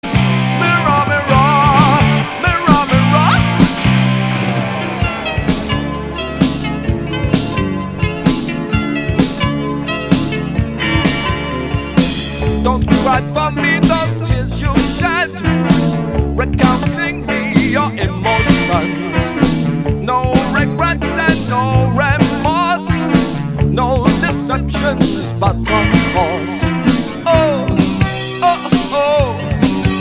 Néo- progressif